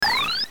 Item Pickup / Key